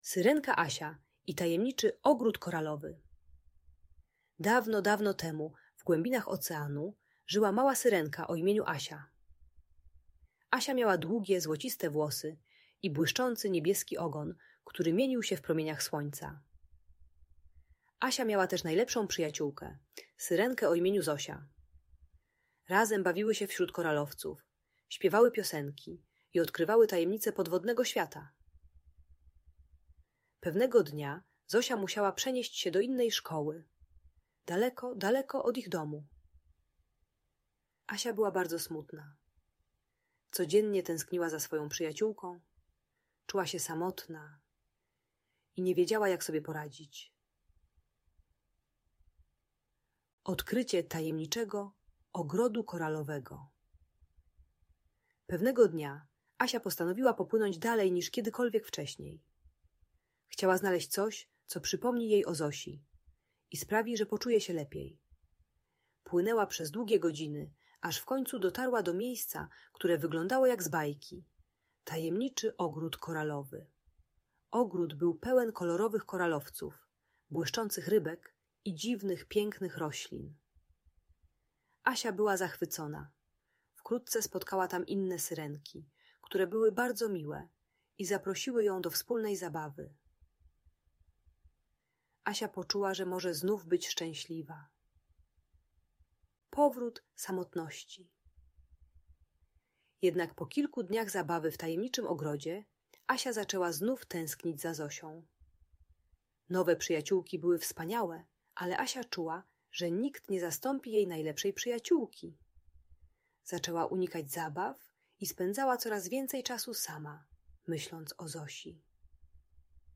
Opowieść o Syrenie Asii i Tajemniczym Ogrodzie Koralowym - Audiobajka